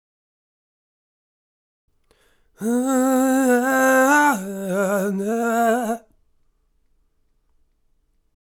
Index of /99Sounds Music Loops/Vocals/Melodies